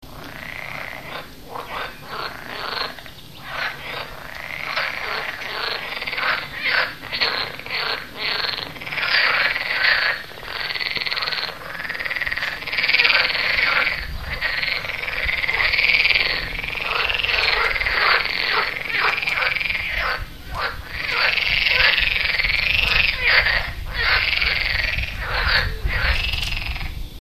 rana_esculenta.mp3